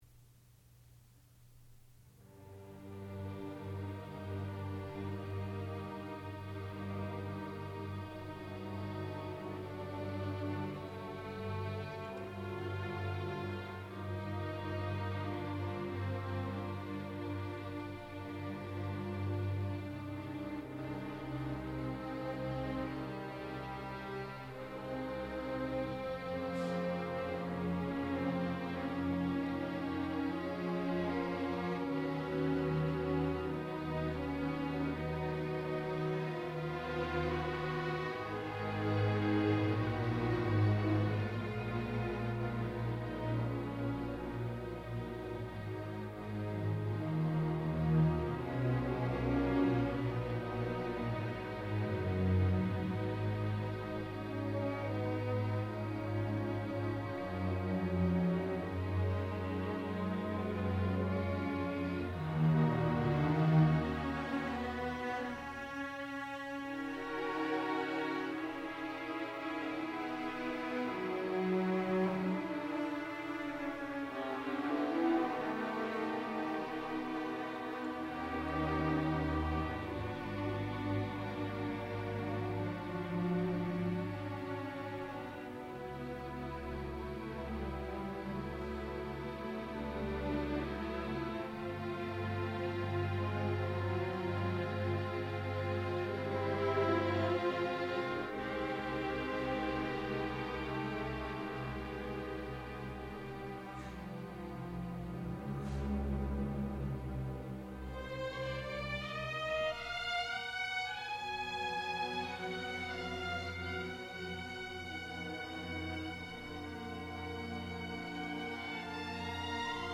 Elegy for String Orchestra
sound recording-musical
classical music